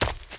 STEPS Leaves, Run 25.wav